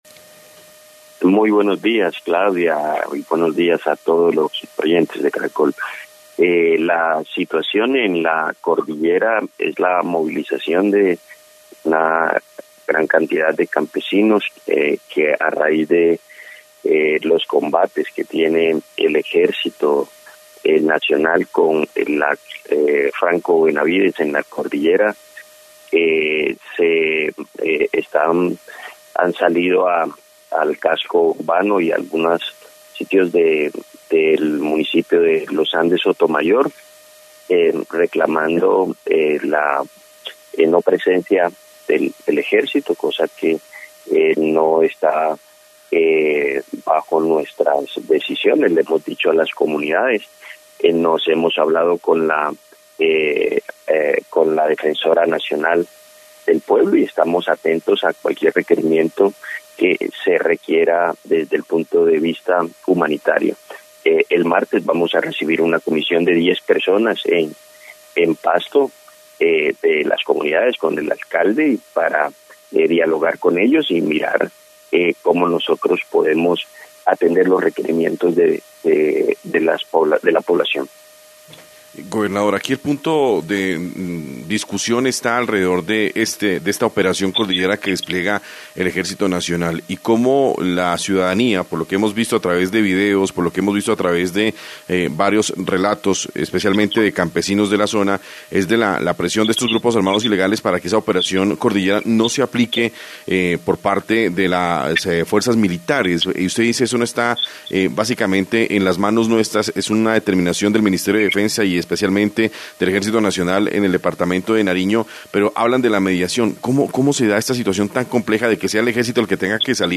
Luis Alfonso Escobar-gobernador de Nariño
En diálogo con Caracol Radio el gobernador de Nariño Luis Alfonso Escobar, dijo que este martes se recibirá una comisión de diez personas de la zona en la ciudad de Pasto, además del alcalde del municipio de los Andes para atender los requerimientos de la comunidad.